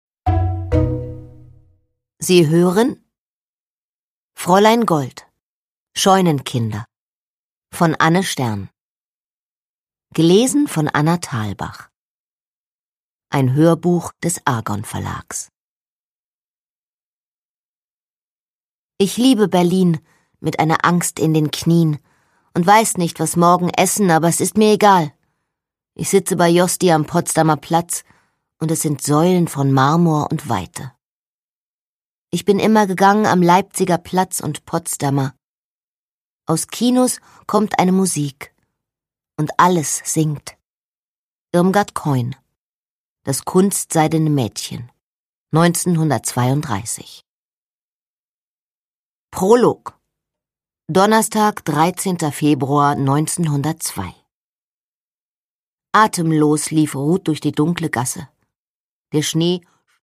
Produkttyp: Hörbuch-Download
Gelesen von: Anna Thalbach